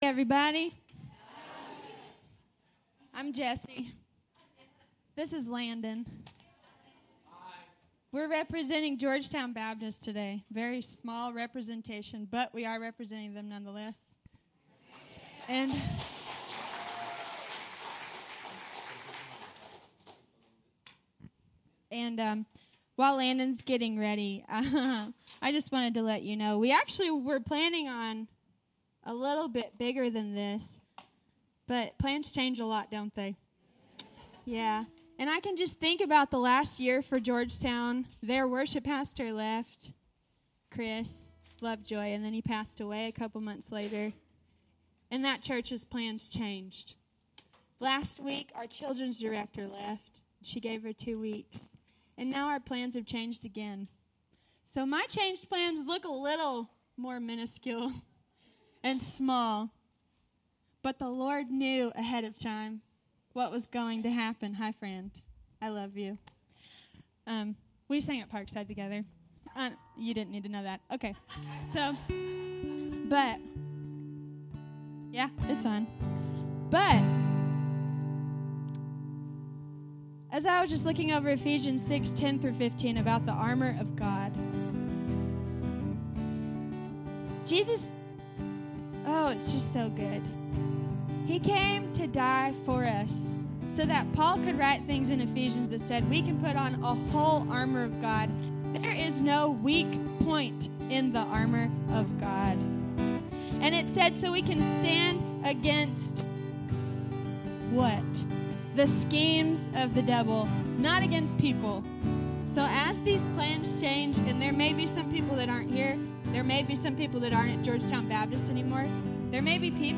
Bible Text: Colossians 3:2 | A night of worship where multiple churches came together to celebrate our Lord and Savior!